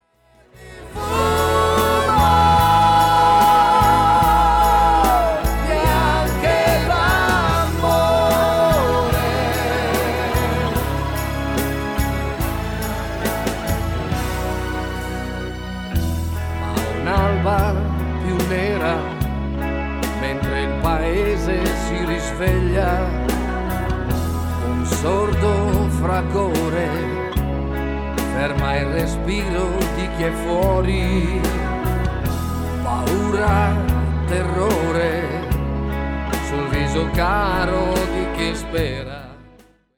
SLOW  (4.15)